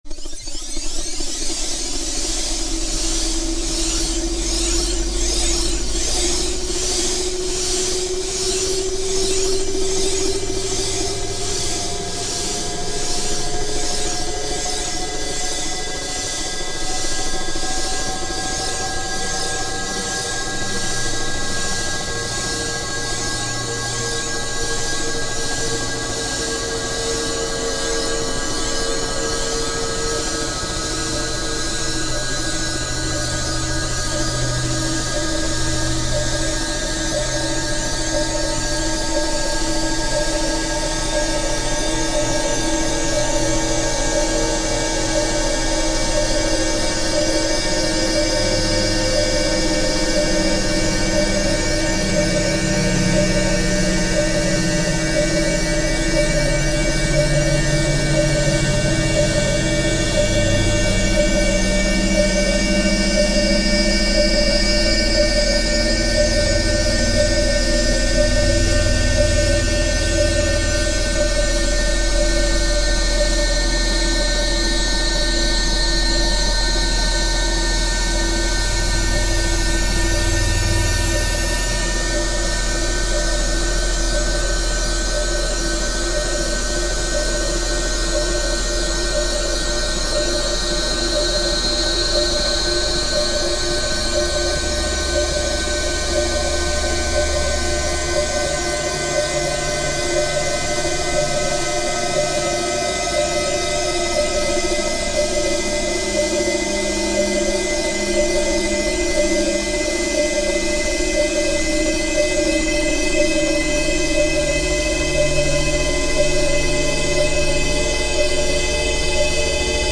But this is a specialized synthesizer designed to produce ambient sound textures, and I’m planning only two voices, which would be appropriate to call layers. This is quite enough to create ambient compositions, which you can listen to using this example.